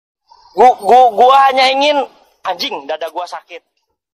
Efek suara Anjing dada gw sakit
Kategori: Suara viral